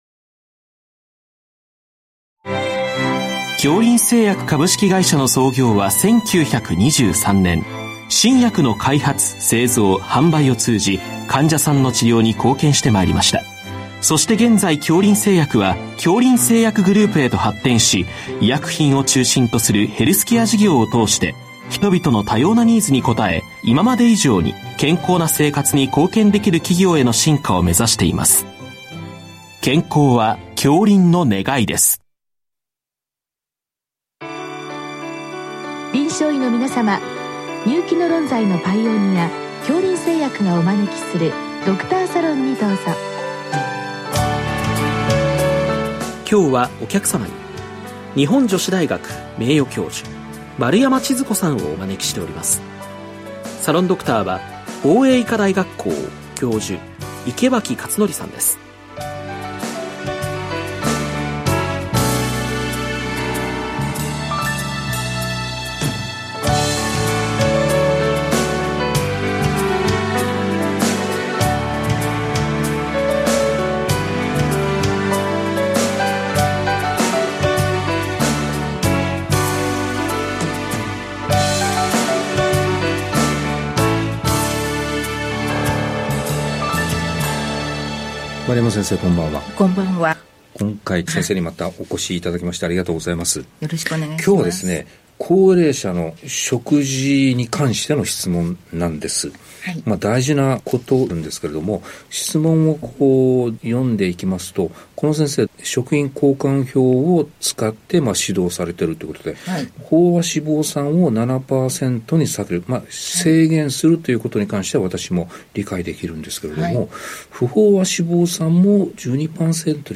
（解 説）
（ききて）